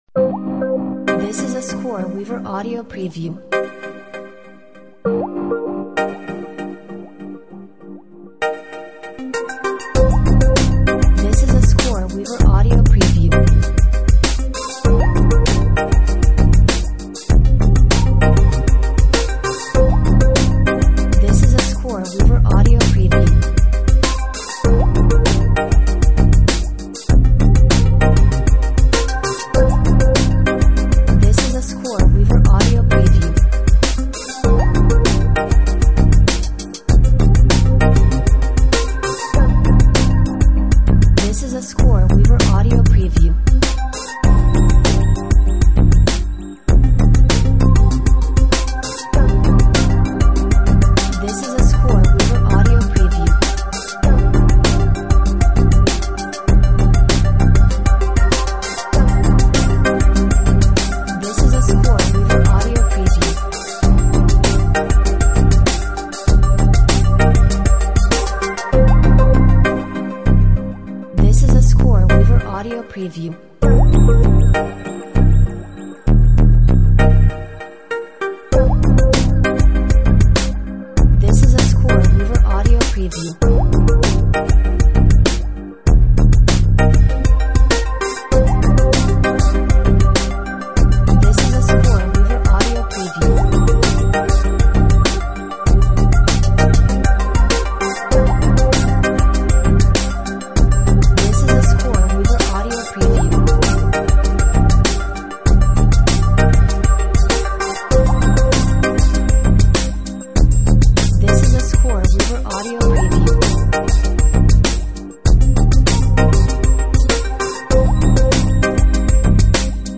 Elegant lounge music with European Electronica flavors!